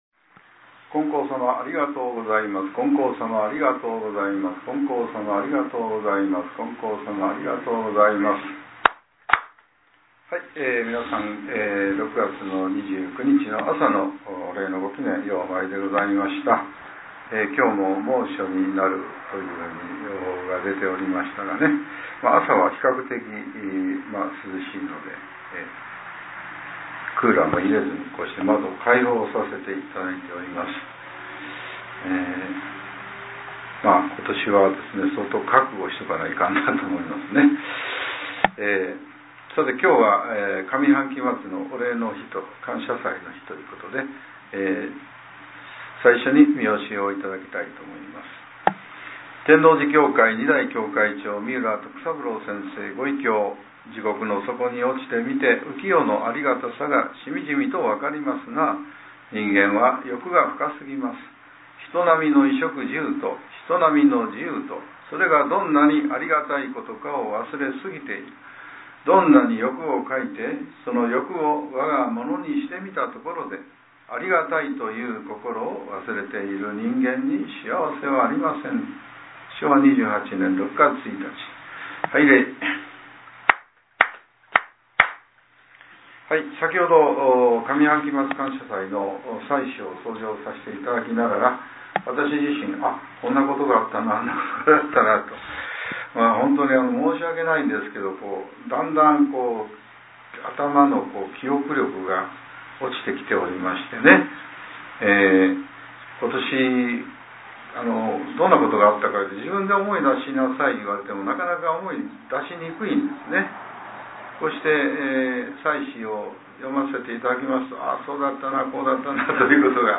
令和７年６月２９日（朝）のお話が、音声ブログとして更新させれています。